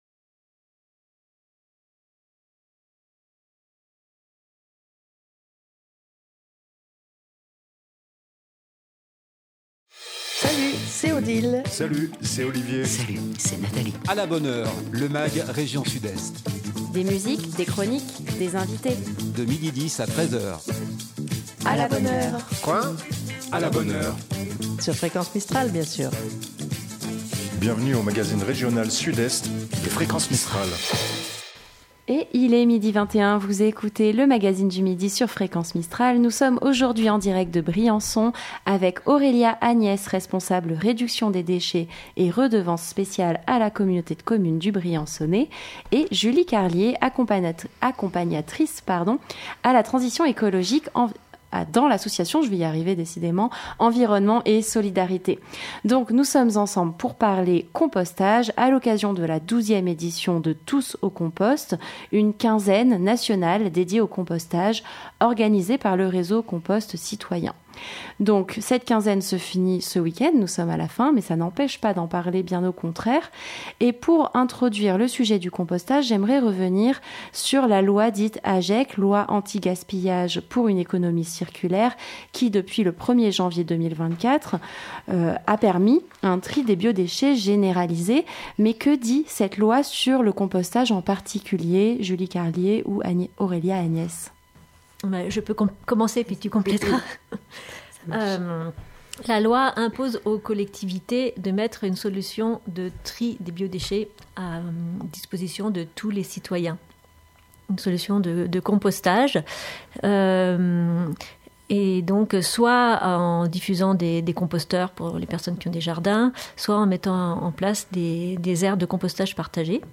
des invité.e.s en direct